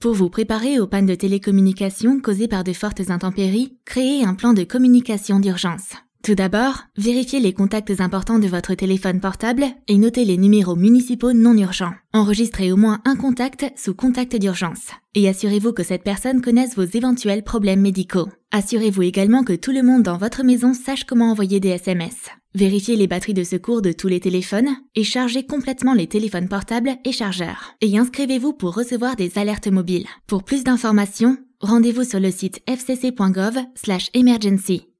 Planification préalable PSA, 30 secondes, audio